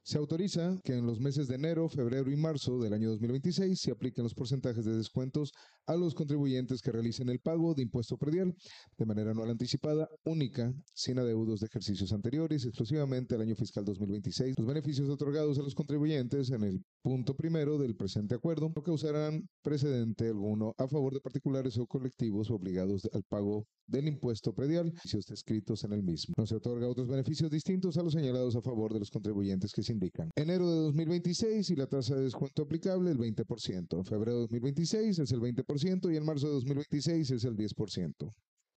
INSERT-SESION-CABILDO-PRESUPUESTO-EGRESOS-2026.mp3